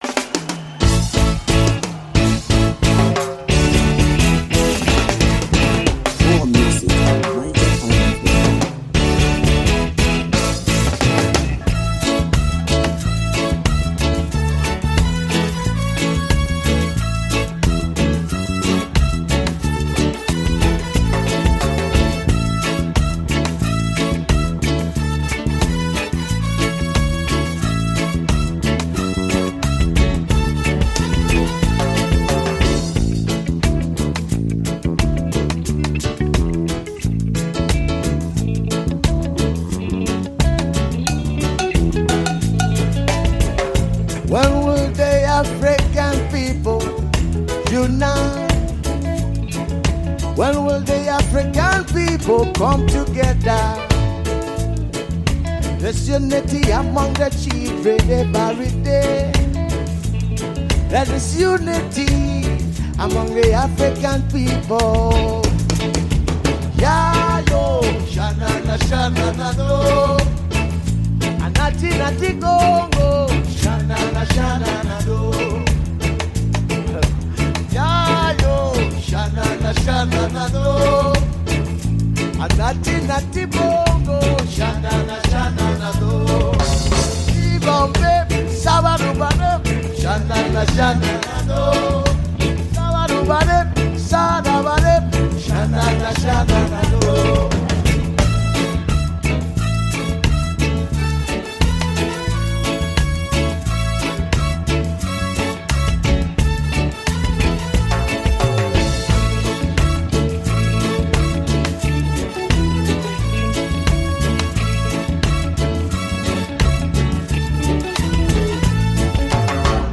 Home » Ragae
Wonderful Reggae Music